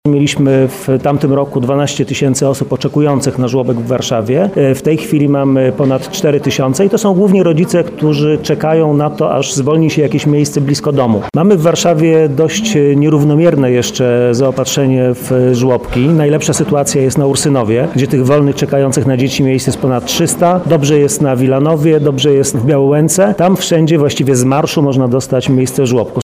Wiceprezydent Warszawy, Paweł Rabiej: